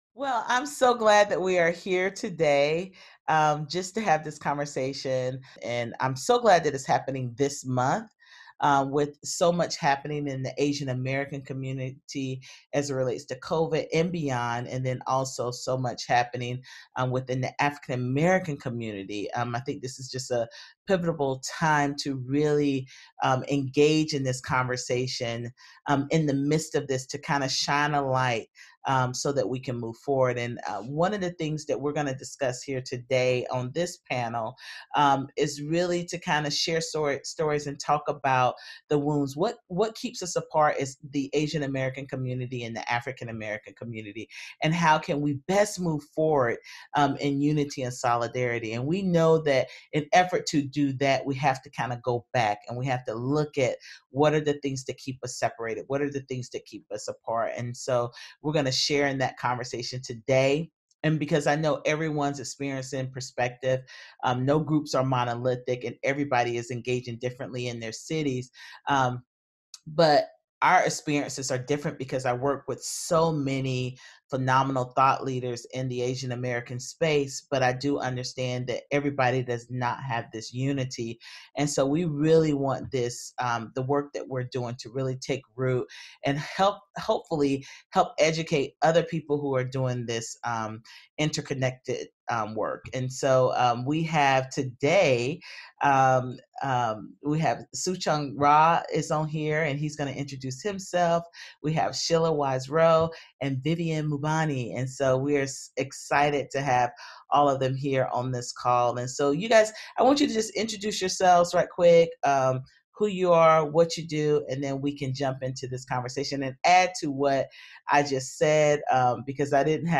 Discussion Panel